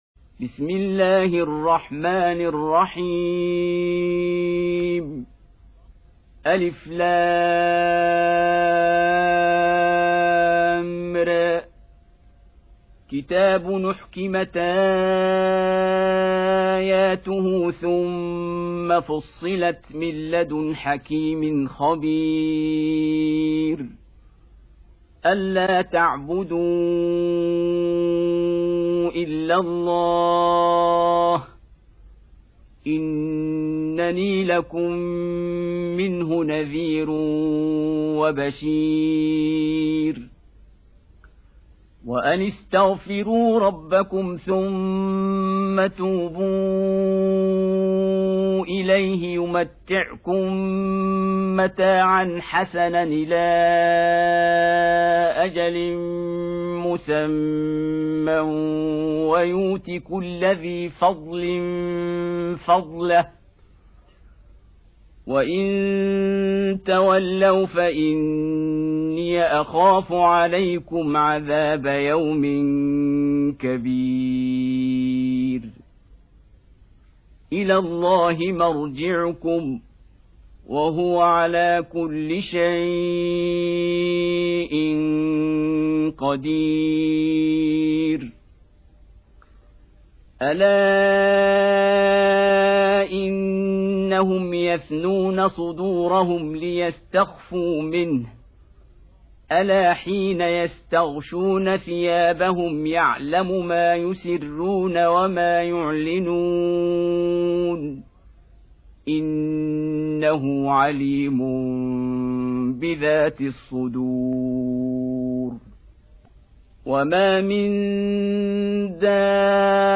11. Surah H�d سورة هود Audio Quran Tarteel Recitation
Surah Repeating تكرار السورة Download Surah حمّل السورة Reciting Murattalah Audio for 11. Surah H�d سورة هود N.B *Surah Includes Al-Basmalah Reciters Sequents تتابع التلاوات Reciters Repeats تكرار التلاوات